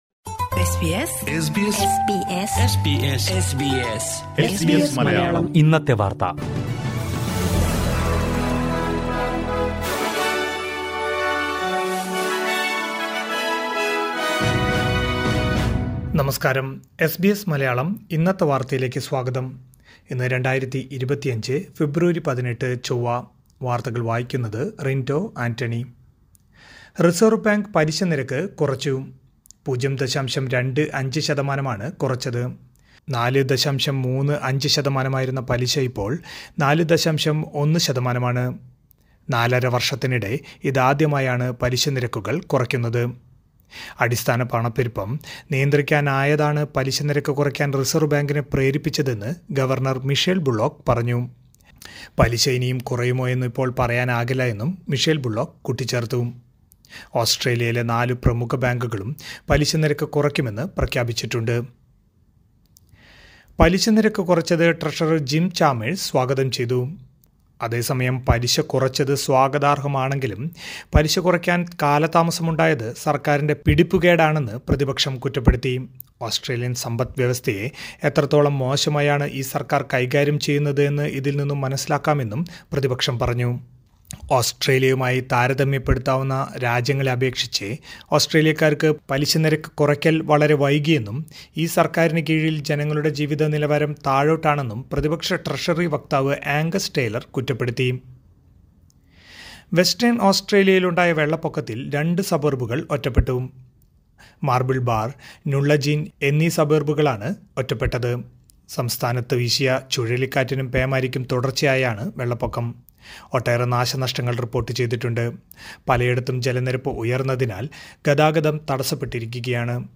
2025 ഫെബ്രുവരി 18ലെ ഓസ്‌ട്രേലിയയിലെ ഏറ്റവും പ്രധാന വാര്‍ത്തകള്‍ കേള്‍ക്കാം...